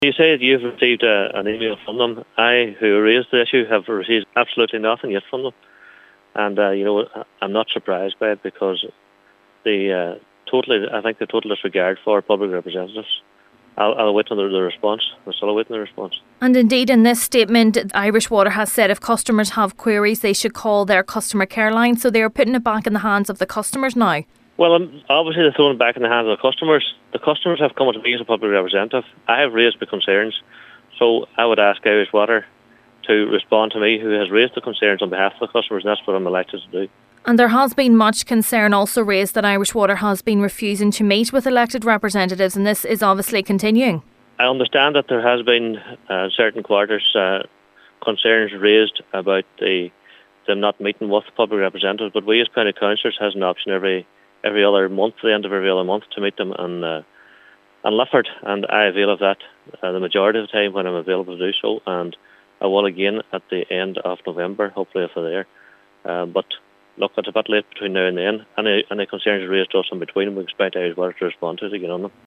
However, Councillor Liam Blaney, who called for urgent clarity on the matter says he has yet to receive a response from the utility: